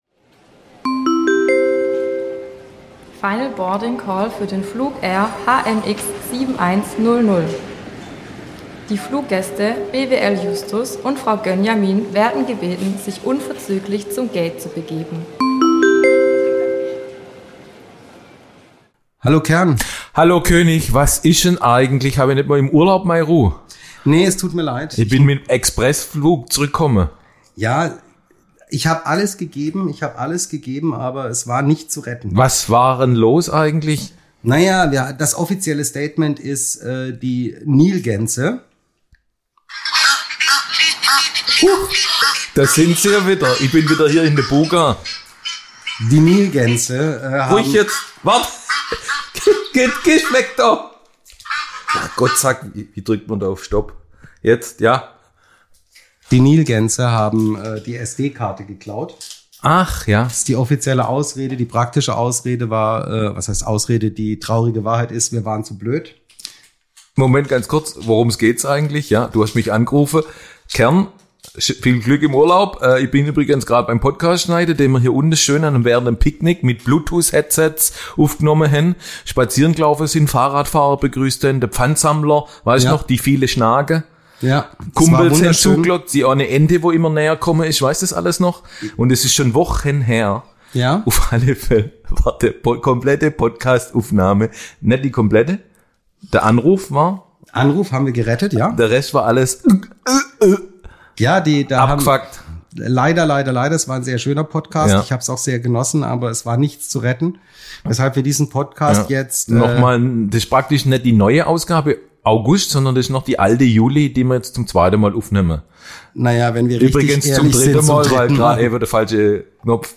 Und als Special Guest per Starlink zugeschaltet